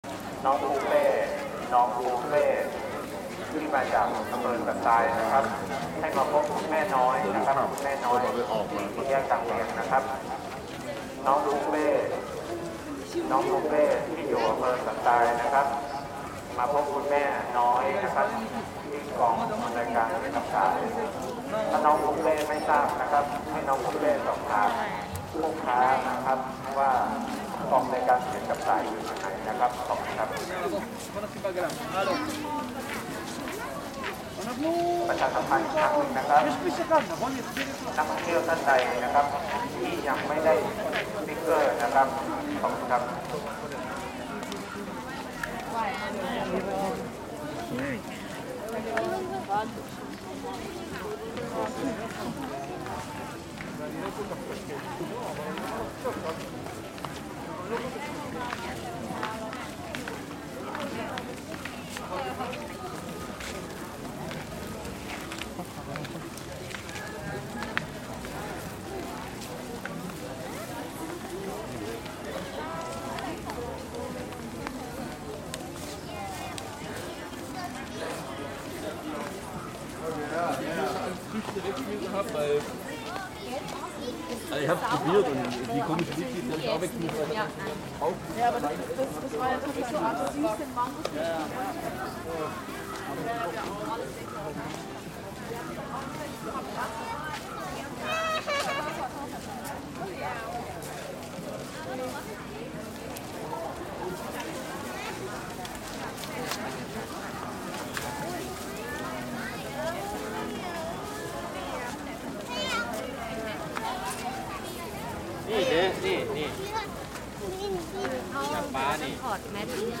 Street market and Thai announcements
The bustling sounds of the street market with a public announcement in the foreground.